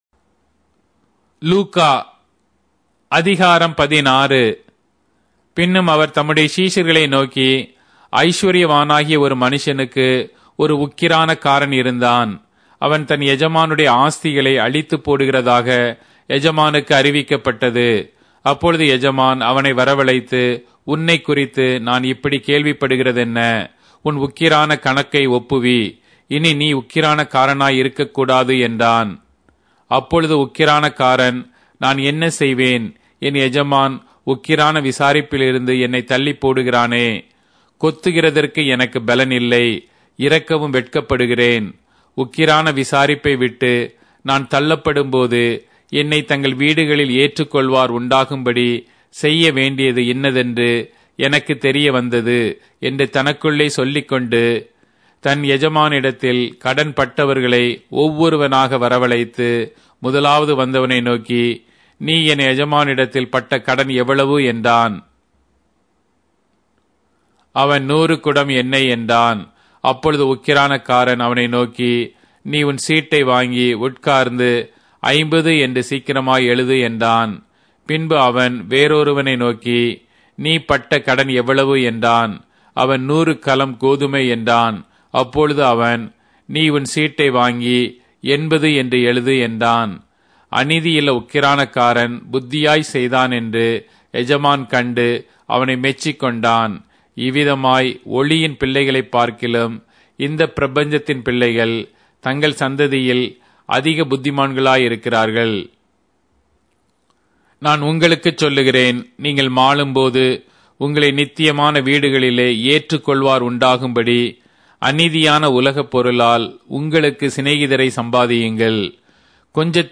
Tamil Audio Bible - Luke 18 in Ervte bible version